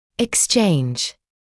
[ɪks’ʧeɪnʤ][икс’чэйндж]обмен; обменивать(ся)